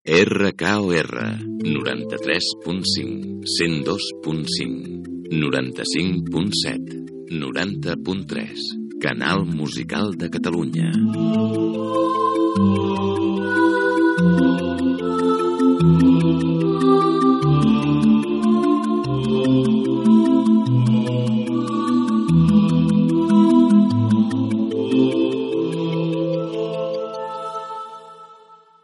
Identificació i freqüències d'emissió de la cadena